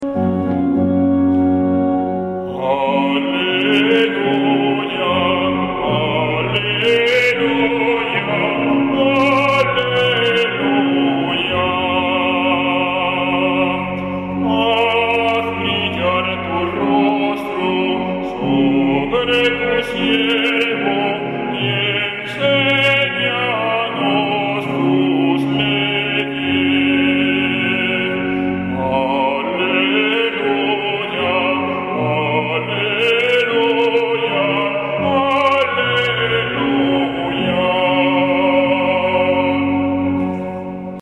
Aleluya.
domingo23caleluya.mp3